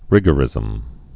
(rĭgə-rĭzəm)